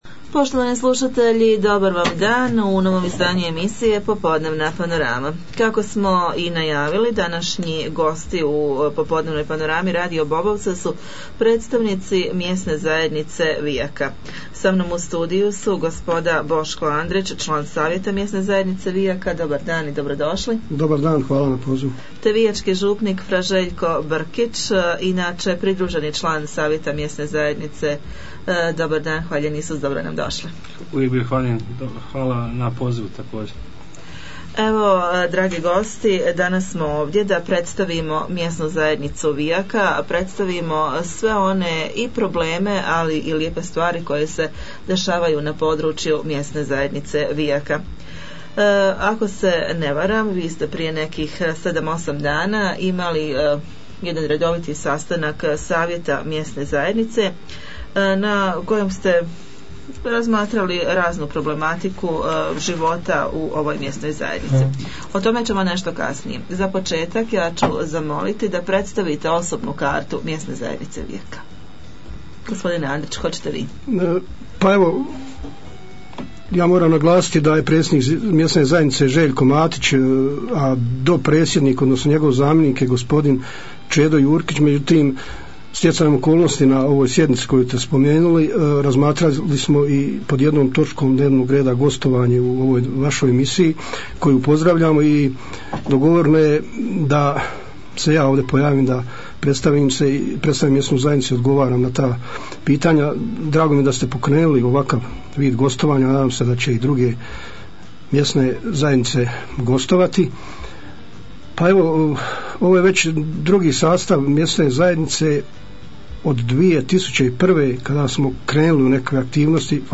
U programu radio Bobovca predstavili smo i Mjesnu zajednicu Vijaka, razgovarali smo s predsjednikom Zdravkom Maroševićem....